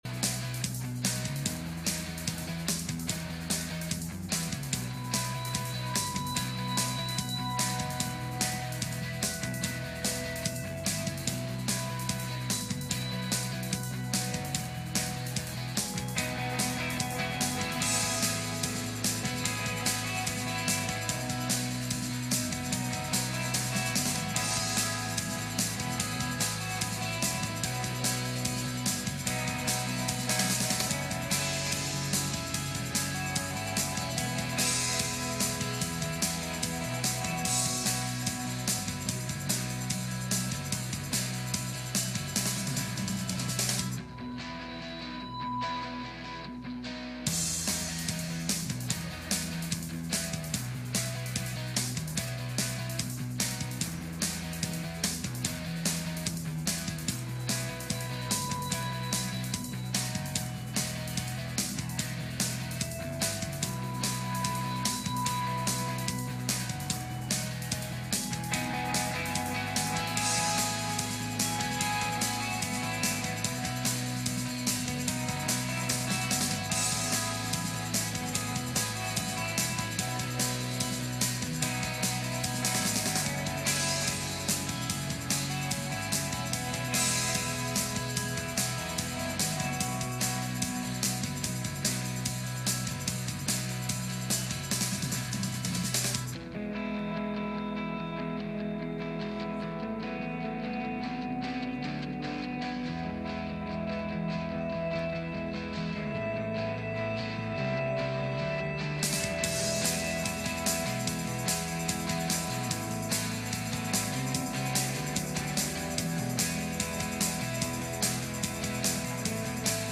2 Kings 5:2 Service Type: Sunday Morning « A Warning About Wolves